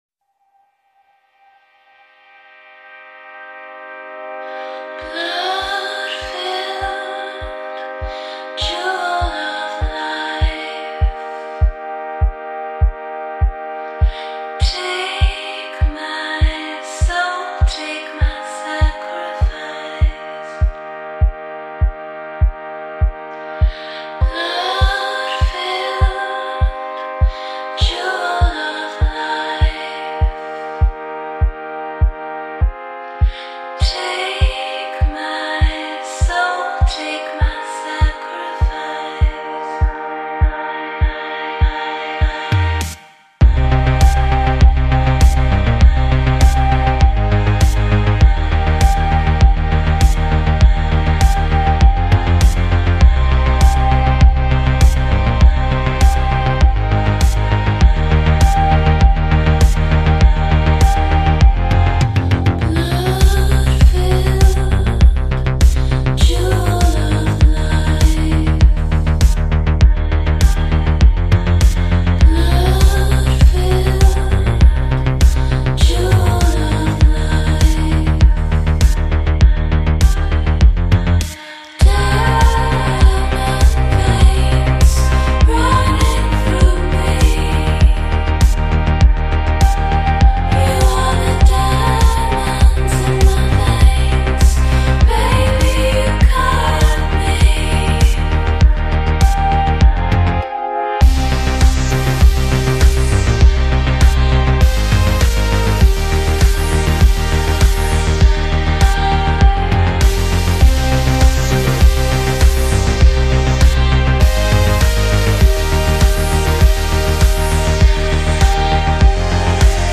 # Electronique : ...